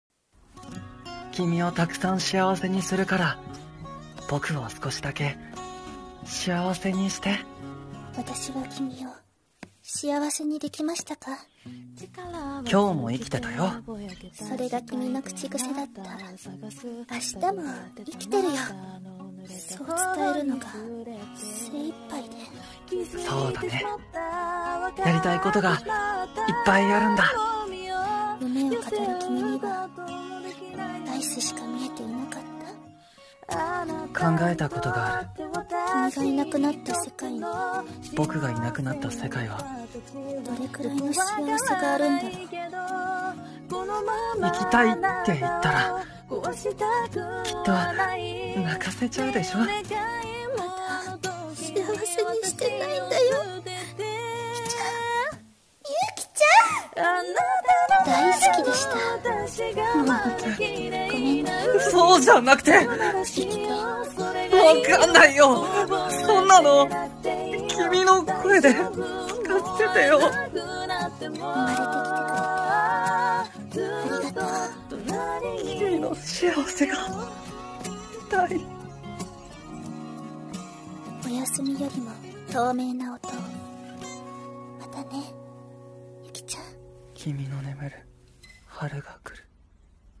【映画予告風声劇】おやすみよりも透明な音を、